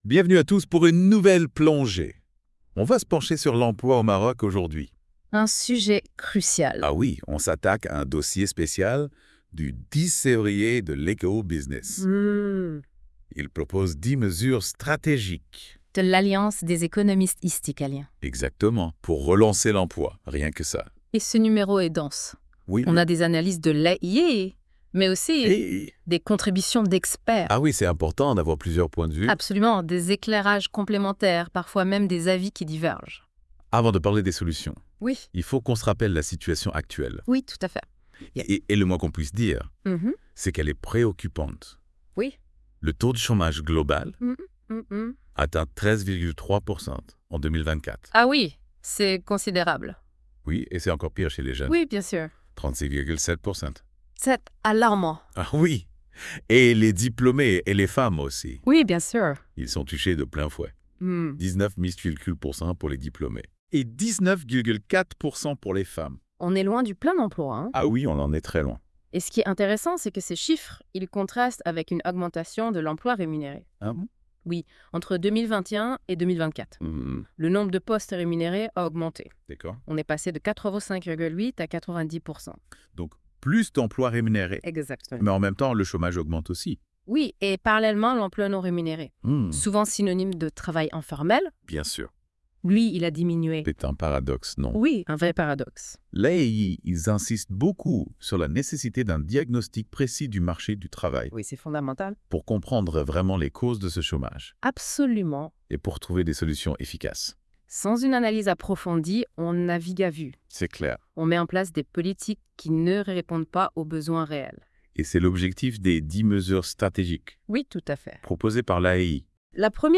Débat (68.7 Mo)